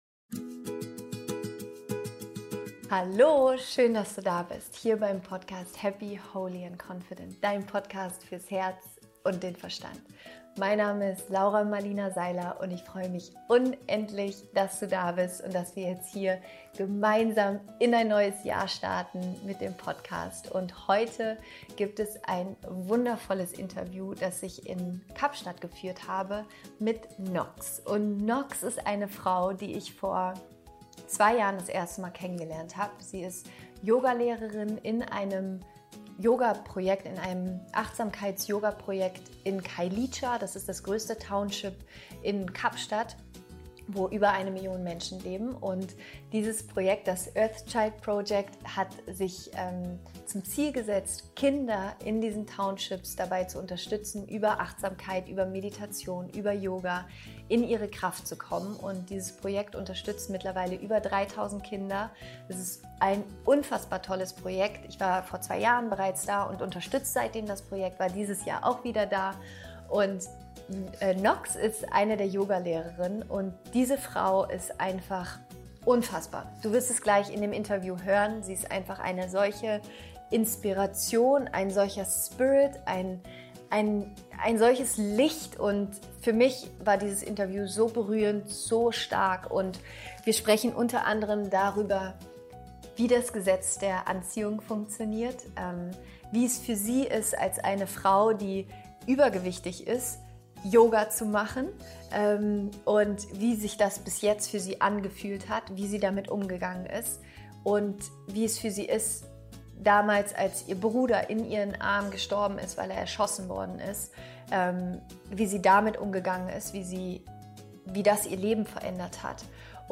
** Das Interview ist auf Englisch.